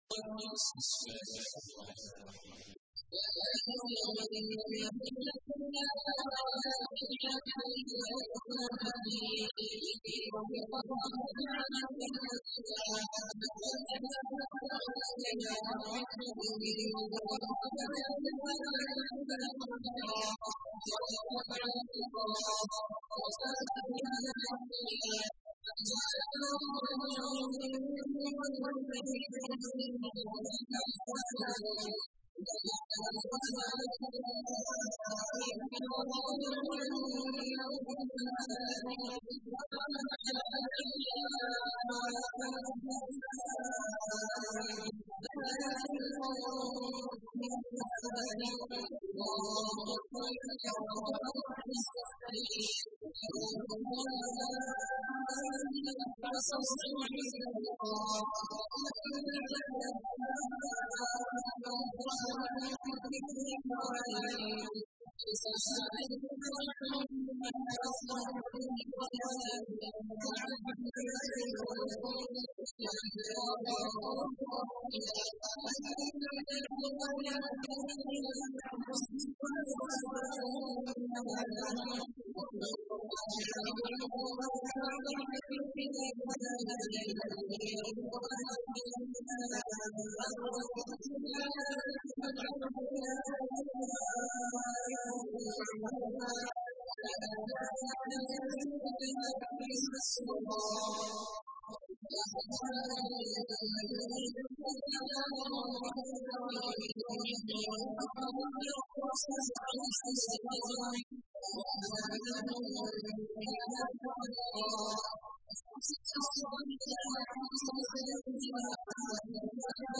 تحميل : 33. سورة الأحزاب / القارئ عبد الله عواد الجهني / القرآن الكريم / موقع يا حسين